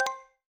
Coins (21).wav